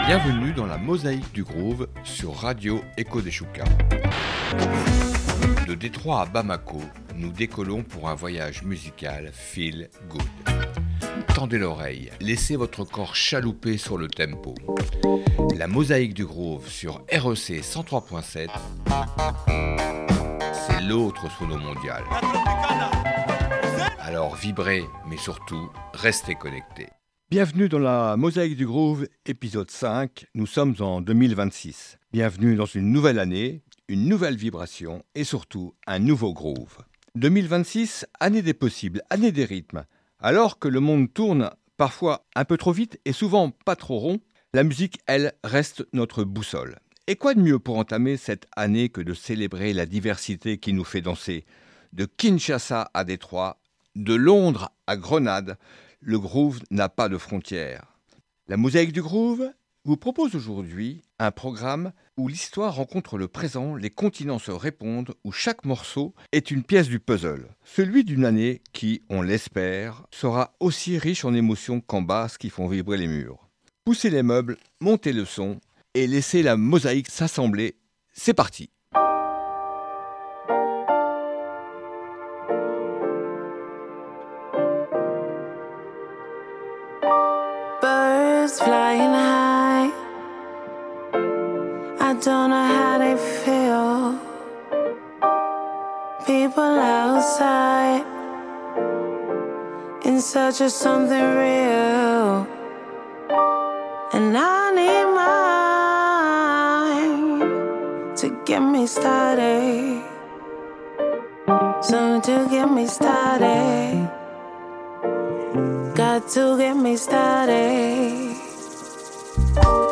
Dans ce nouvel épisode de la Mosaïque du Groove , 2026 débute dans un monde définitivement groovy: du funk, de la deep-house, de la rumba congolaise et autres rythmes qui vont chalouper vos oreilles…..